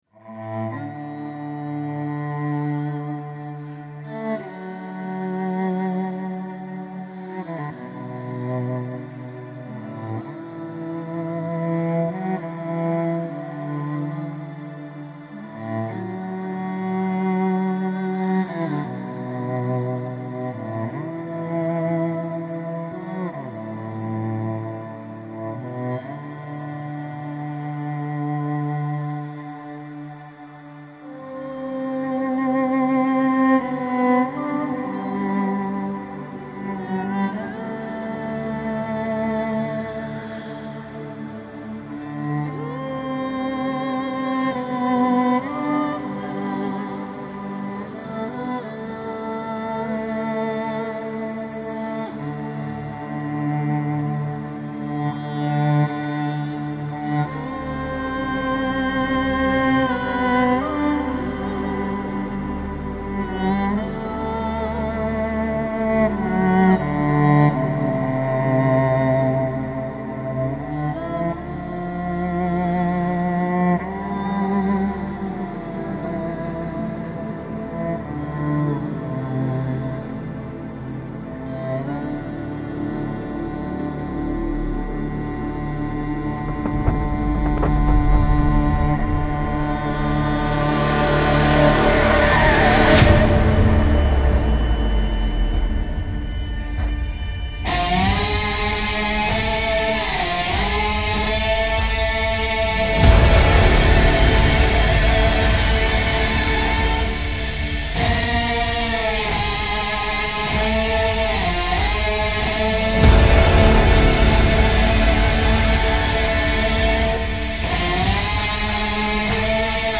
• Жанр: Instrumental